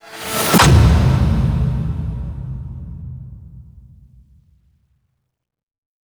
syssd_se_shutter_seni.wav